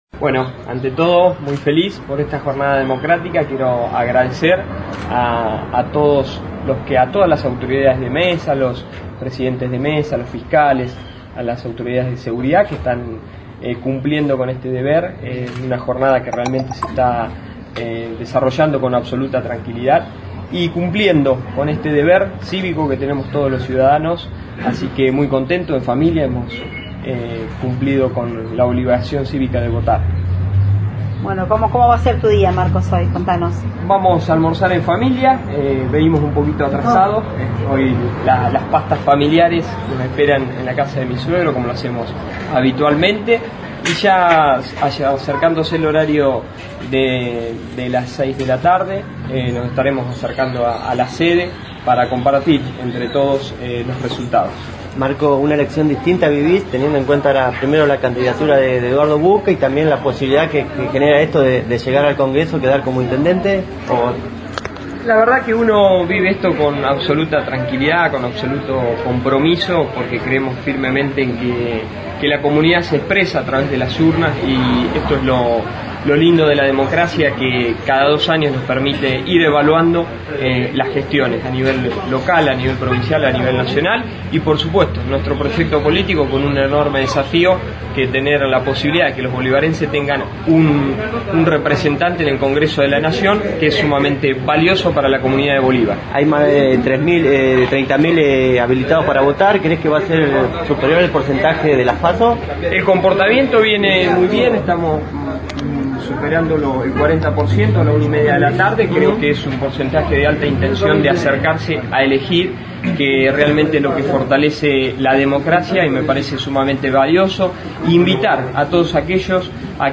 LA PALABRA DEL INTENDENTE INTERINO DE BOLIVAR